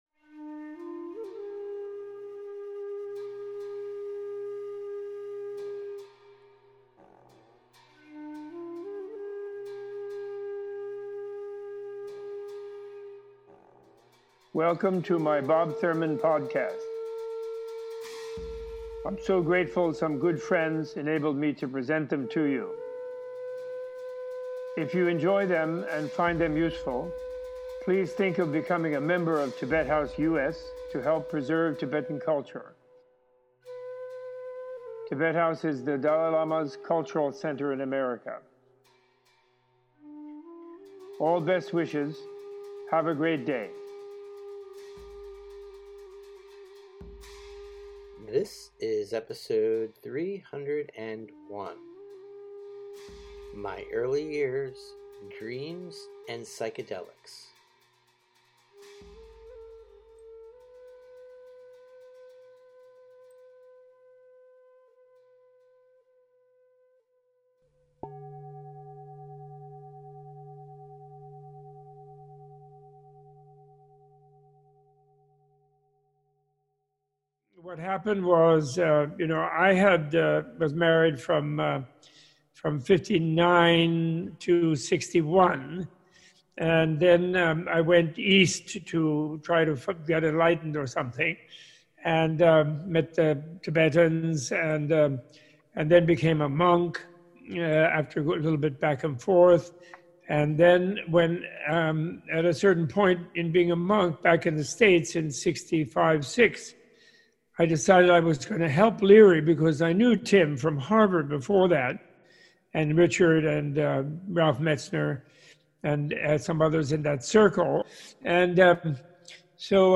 In this episode Robert Thurman discusses his early years studying Buddhism with the Kalmyk-Mongolian lama Geshe Ngawang Wangyal at the Tibetan Buddhist Learning Center Labsum Shedrub Ling in central New Jersey, meeting his wife Nena von Schlebrügge at Millbrook, and his encounters with psychedelic psychonauts Timothy Leary and Ram Dass in the 1960s. Using his classic book The Life and Teachings of Tsongkhapa (now available in a new edition by Wisdom Publications), Thurman discusses emptiness, non-duality, the myth of the Kali Yuga and coming of Shambhala, reincarnation and the Buddhist perspective on the soul.